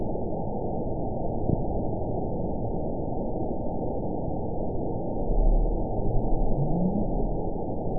event 921799 date 12/19/24 time 03:38:39 GMT (4 months, 2 weeks ago) score 9.51 location TSS-AB04 detected by nrw target species NRW annotations +NRW Spectrogram: Frequency (kHz) vs. Time (s) audio not available .wav